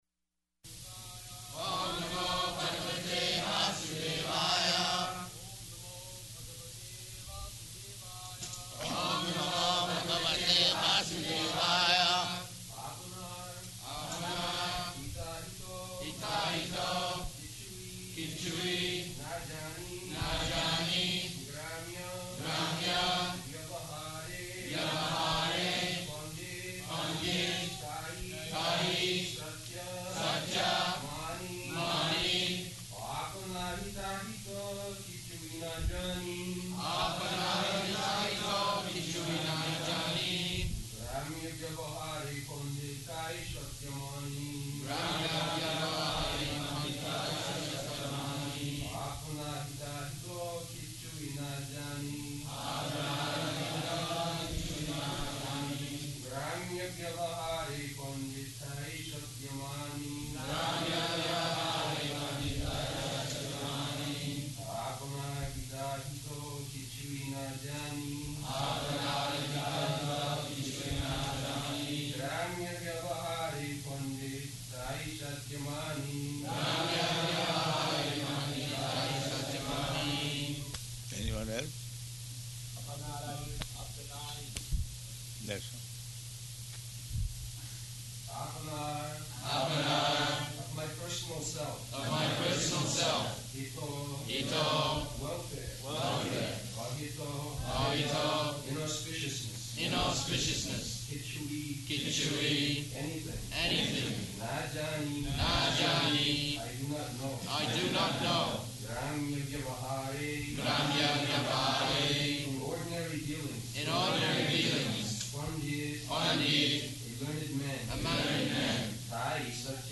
[Prabhupāda and devotees repeat] [leads chanting, etc.] āpanāra hitāhita kichui nā jāna! grāmya-vyavahāre paṇḍita tāi satya māni [ Cc.